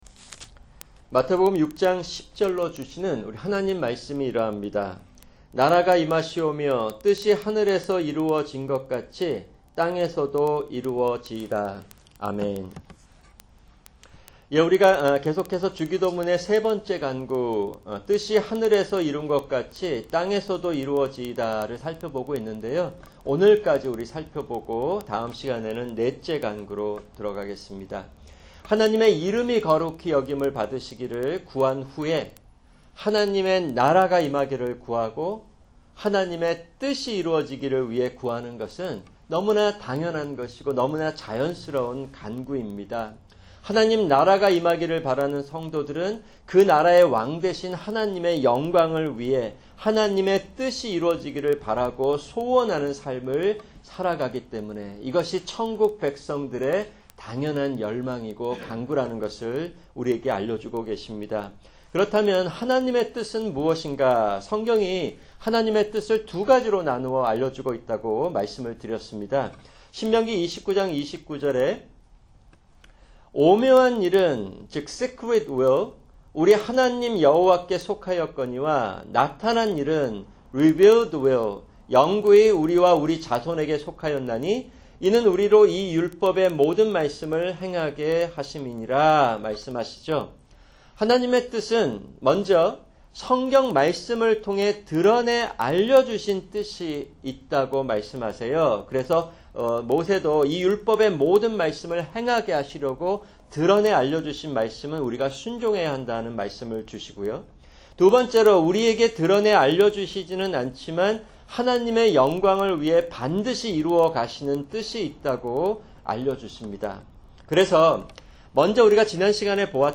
[주일 설교] 마태복음 6:10(5) – 기도(12)